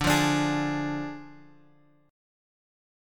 Ddim chord {x 5 3 x 3 4} chord
D-Diminished-D-x,5,3,x,3,4.m4a